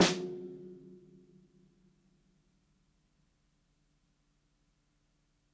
ROOMY_SNARE_SOFT.wav